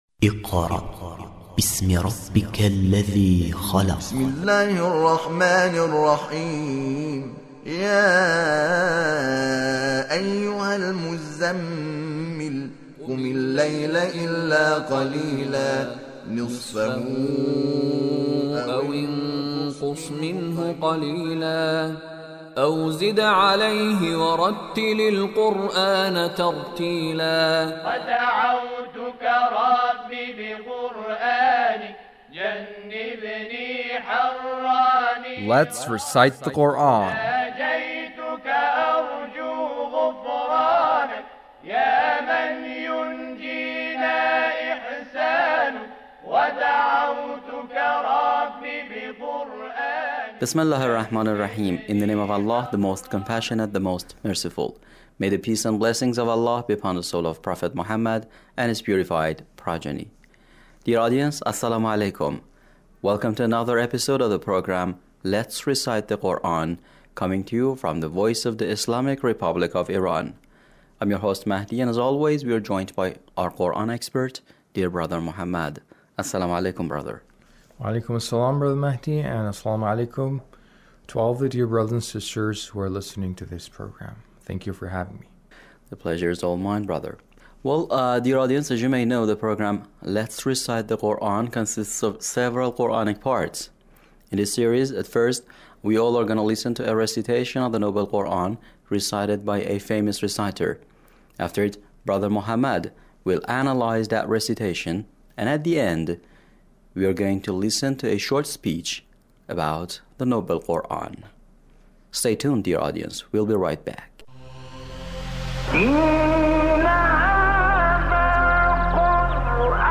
Mohammad Seddiq Menshawi's recitation